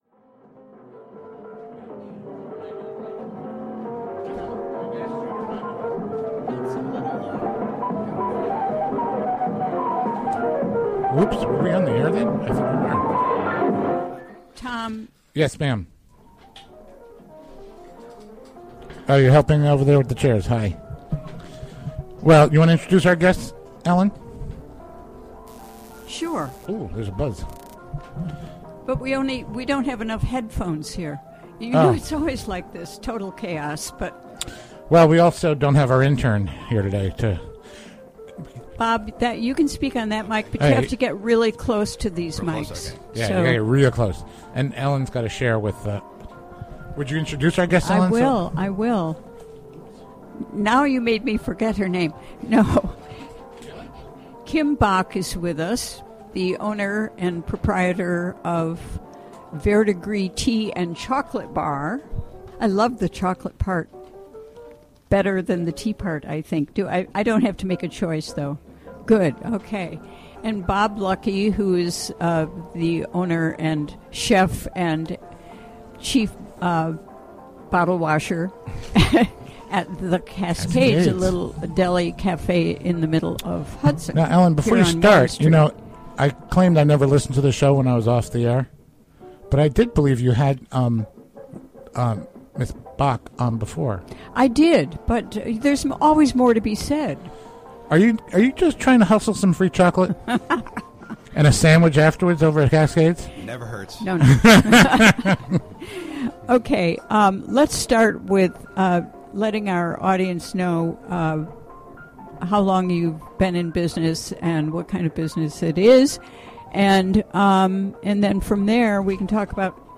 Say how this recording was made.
Recorded during the WGXC Afternoon Show, Thu., Nov. 12, 2015.